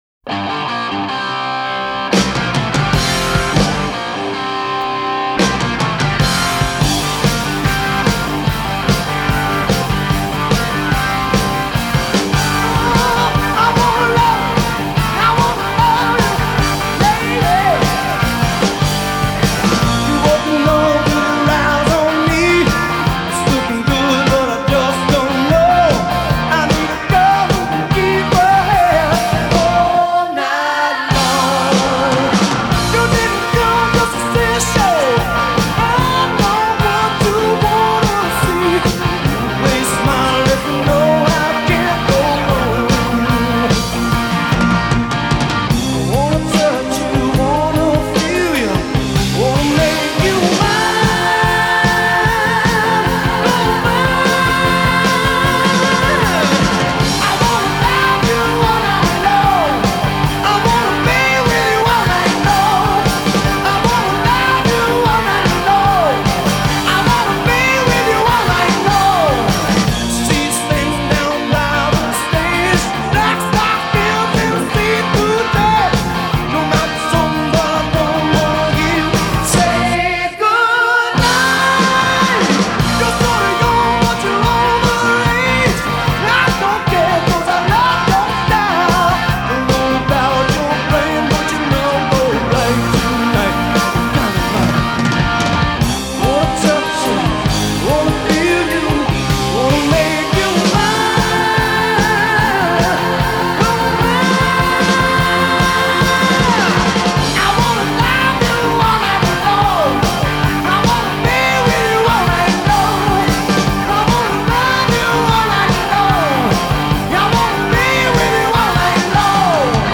Жанр: Хард-рок, AOR